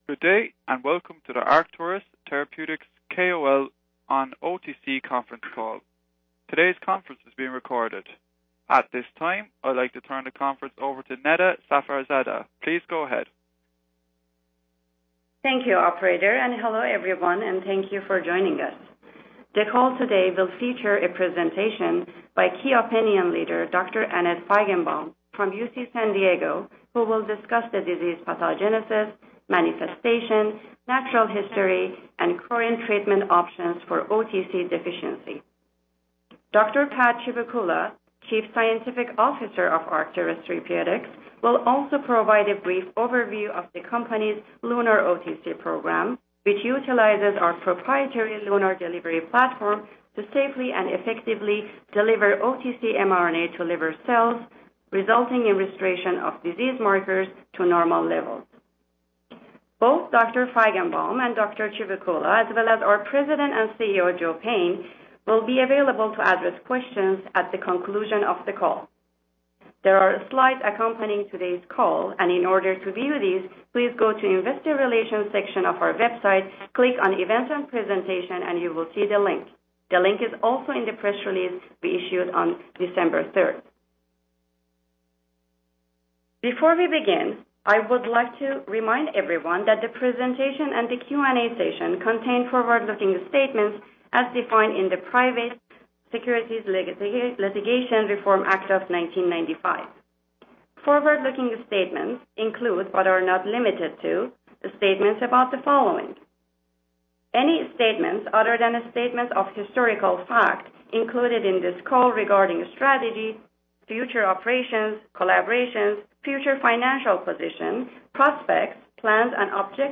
The call will feature a presentation